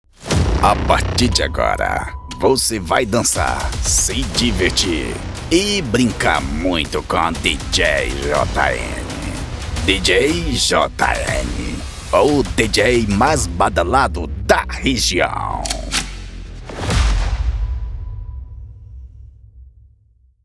VINHETA PARA DJ: